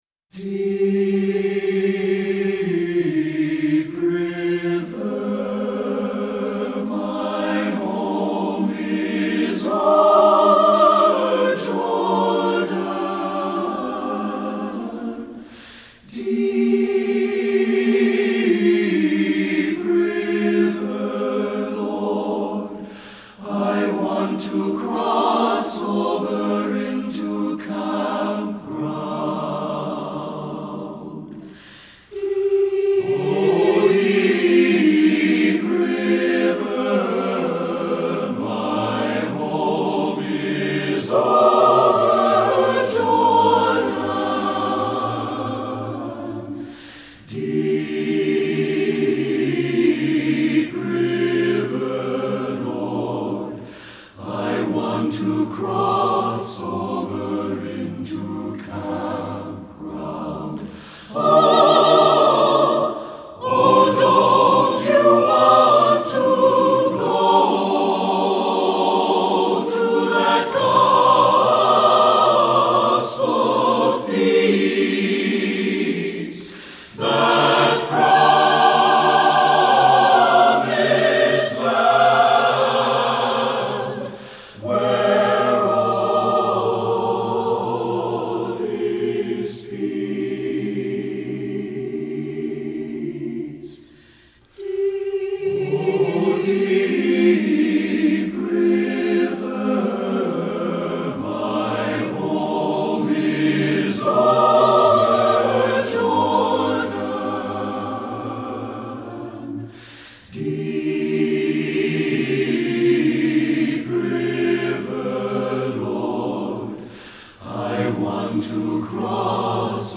Choral Spiritual
Available: SATB, a cappella.
SATB A Cap